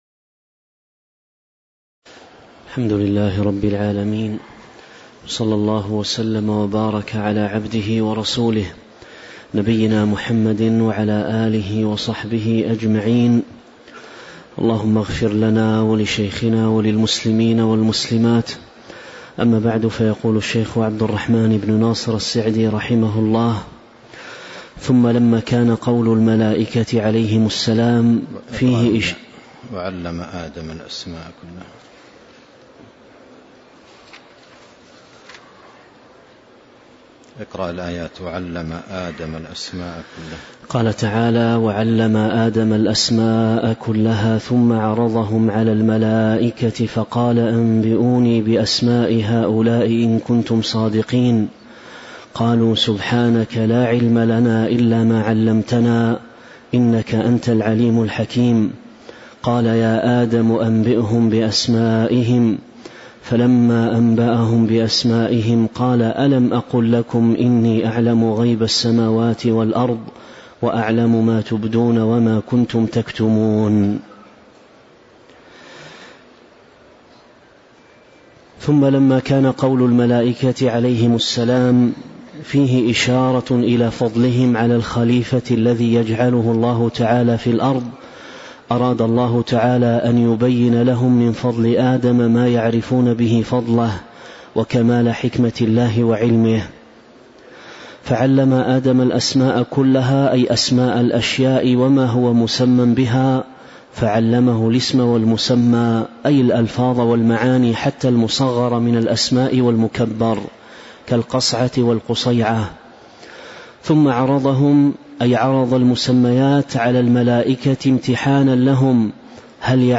تاريخ النشر ٧ ربيع الثاني ١٤٤٦ هـ المكان: المسجد النبوي الشيخ